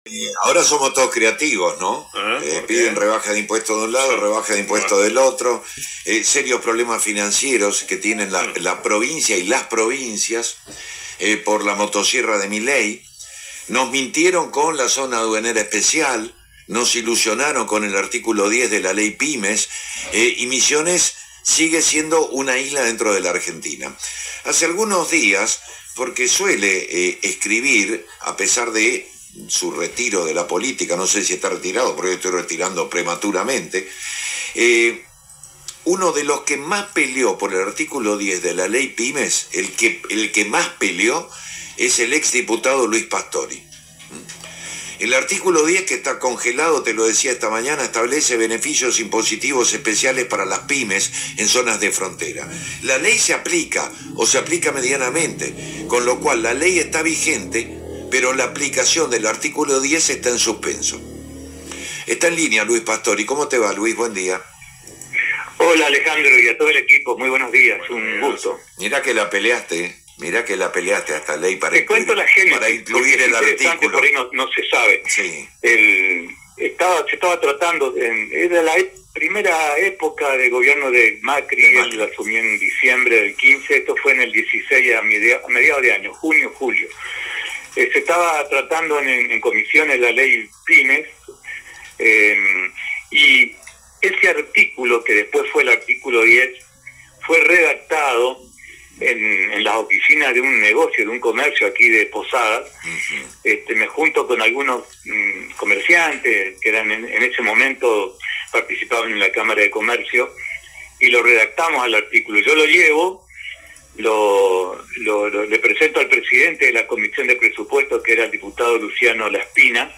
En una entrevista concedida a FM Show de Posadas, Pastori recordó la intensa pelea legislativa que protagonizó para incorporar ese artículo.